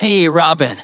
synthetic-wakewords
ovos-tts-plugin-deepponies_Adachi Tohru_en.wav